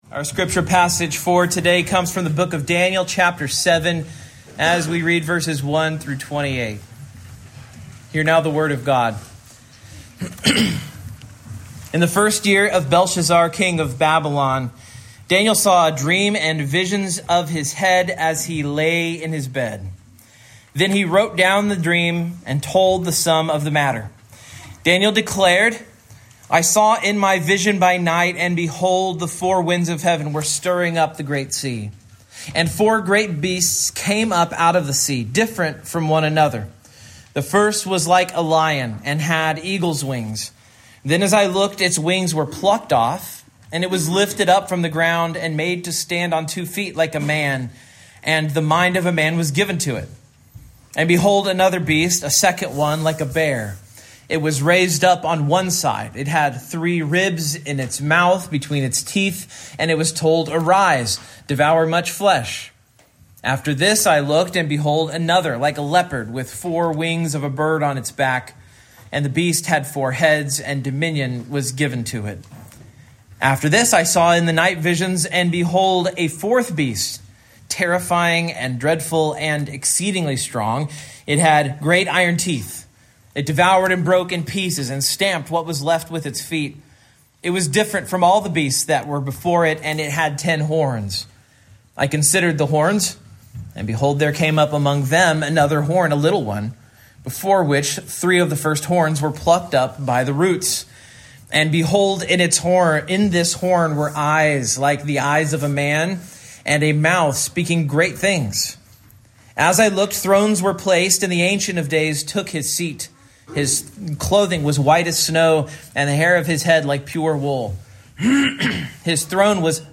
Daniel 7:1-28 Service Type: Morning Main Point